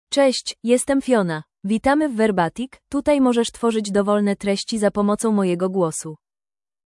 FionaFemale Polish AI voice
Fiona is a female AI voice for Polish (Poland).
Voice: FionaGender: FemaleLanguage: Polish (Poland)ID: fiona-pl-pl
Voice sample
Listen to Fiona's female Polish voice.
Fiona delivers clear pronunciation with authentic Poland Polish intonation, making your content sound professionally produced.